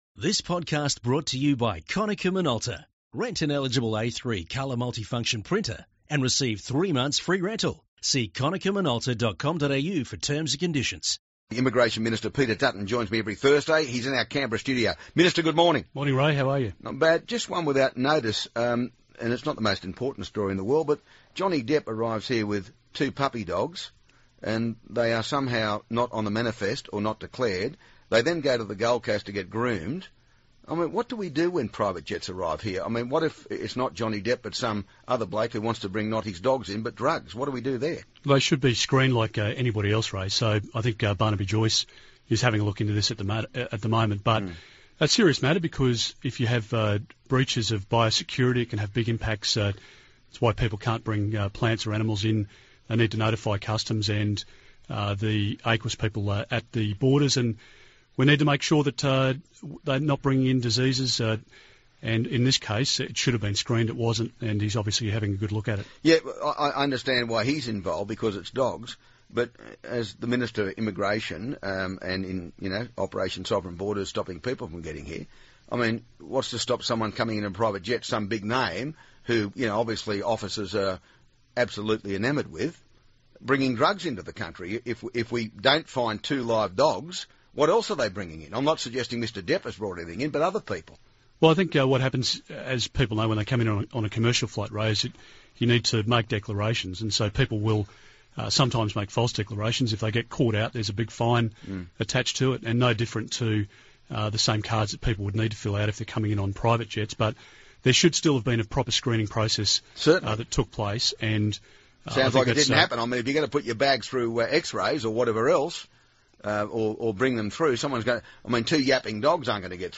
Immigration Minister Peter Dutton talks to Ray from the Canberra studio about Johnny Depp’s dogs, an Australian extremist overseas, Indonesia turning back boats, Europe’s boat crisis and Liberal disunity on paid parental leave